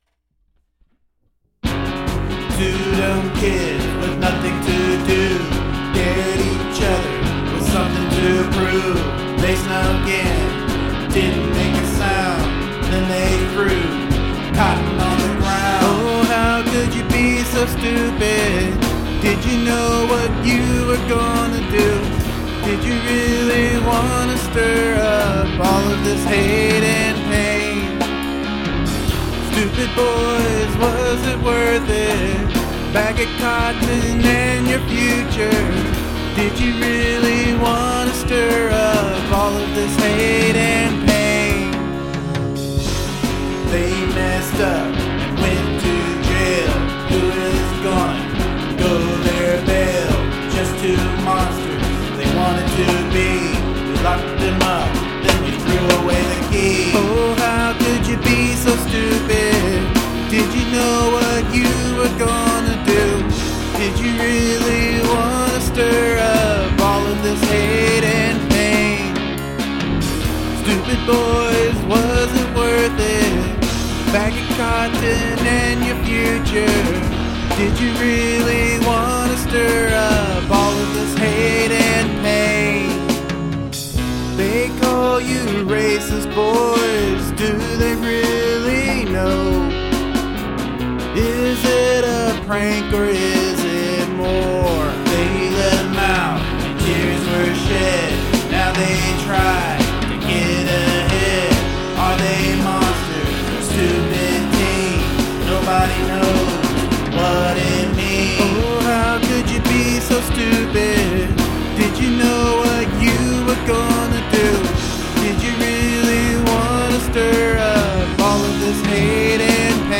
drums
This song is kind of punky!
Cool punky song !
Nice live drums...;...:...;....:...